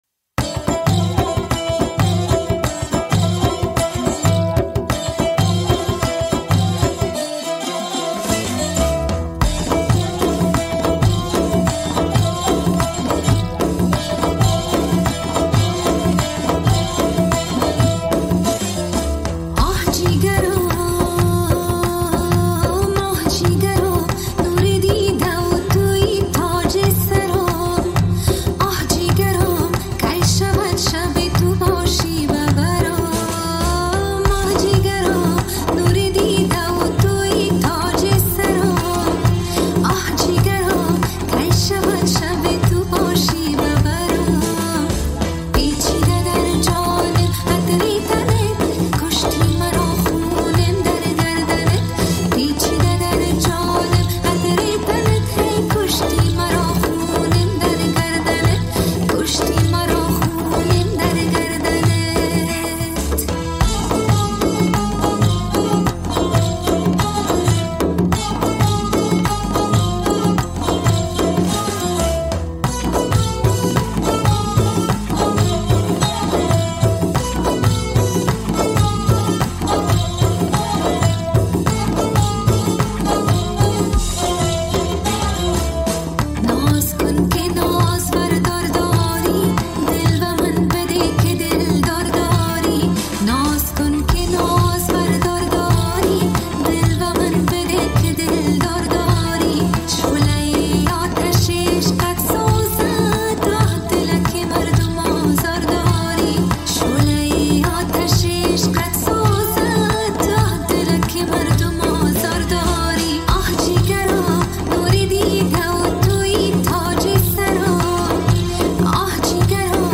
افغانی
آهنگ با صدای زن
اهنگ ایرانی